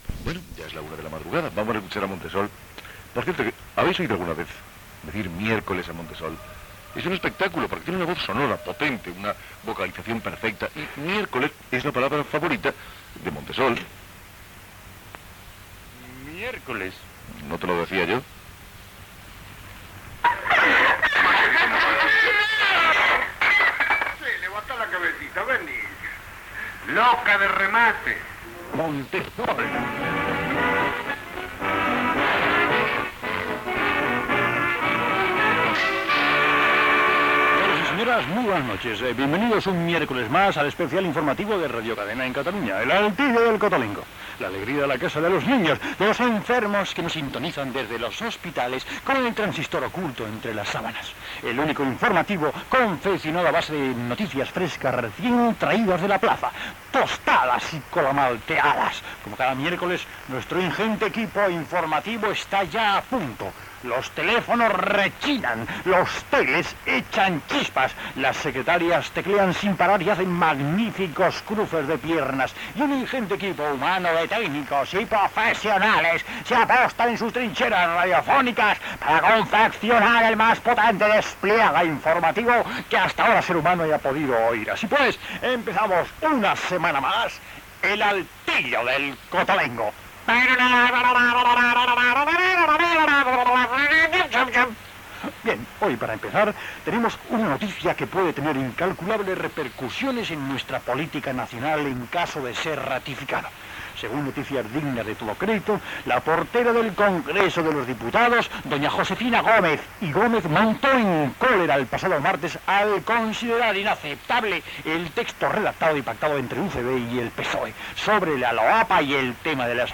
Presentació, careta de la secció "El altillo del cotolengo", espai humorístic dedicat a la política naconal. Identificació de l'emissora
Entreteniment
FM